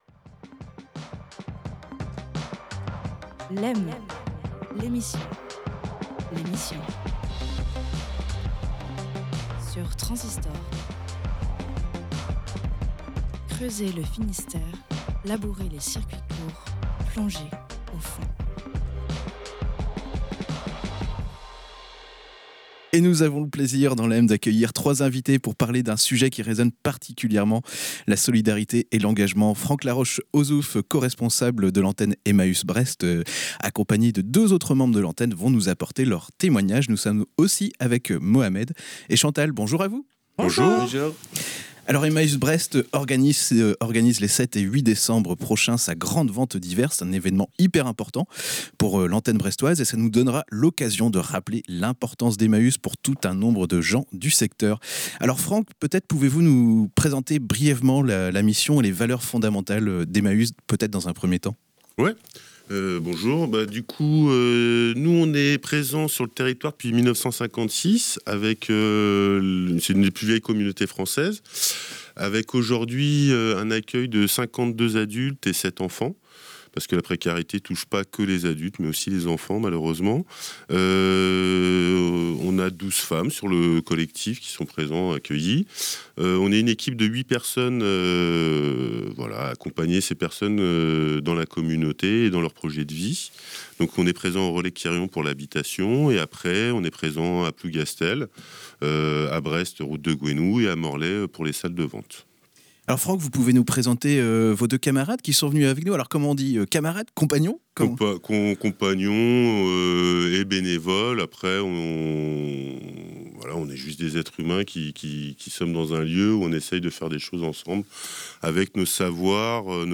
Ensemble, ils ont répondu aux questions sur l’organisation de cet événement et sur la mission d’Emmaüs Brest.